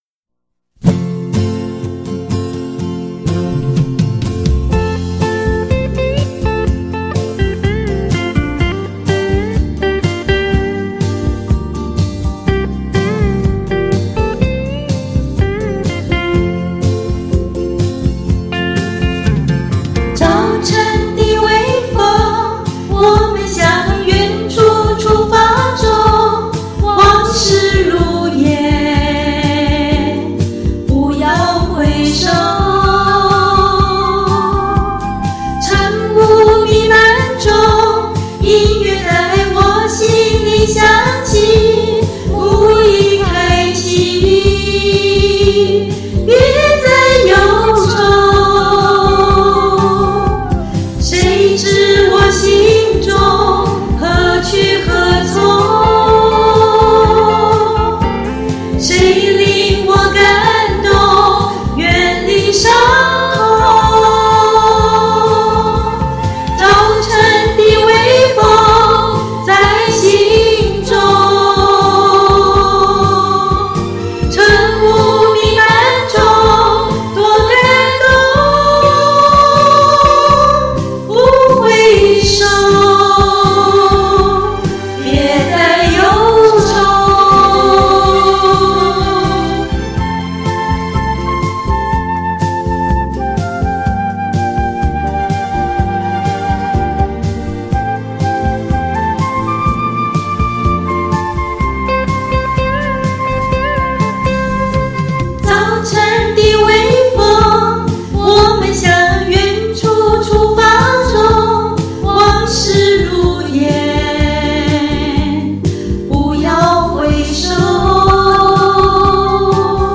两个人的声音很合呢～